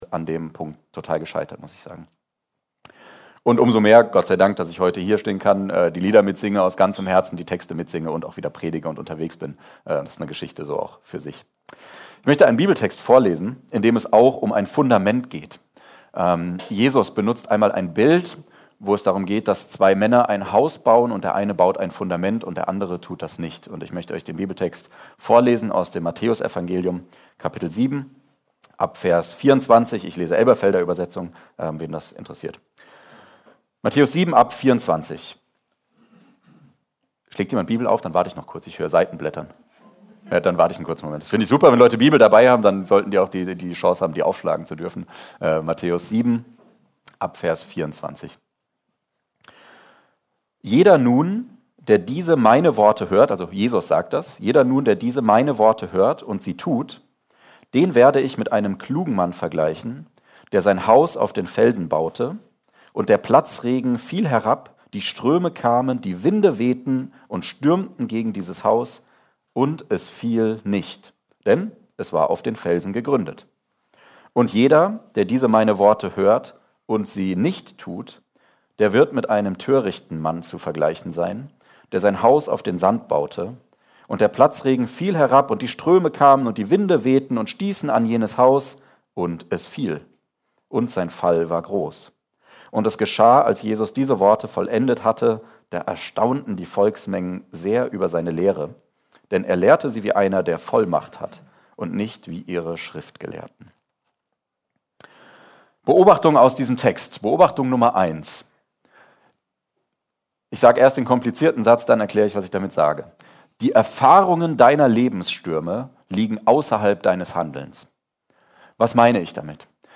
EFG Mühltal – Predigten